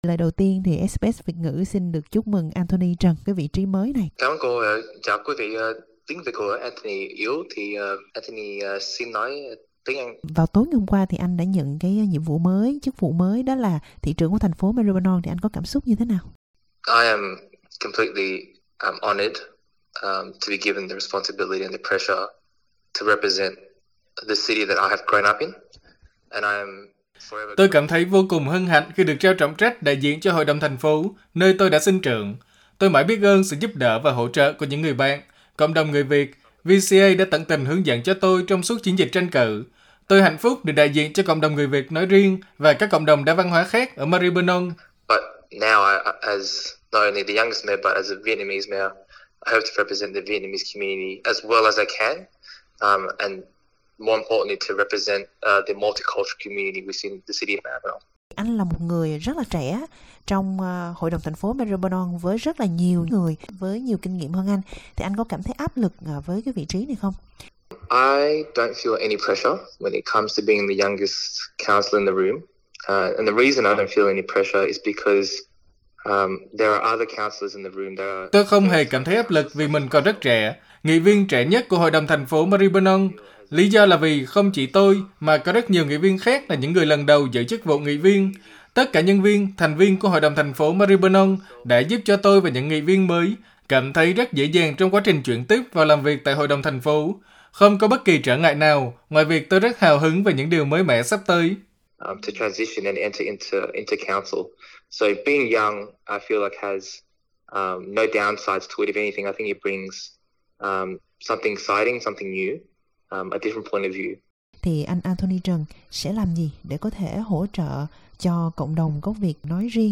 interviewanthony_youngestmayor_vietnamese.mp3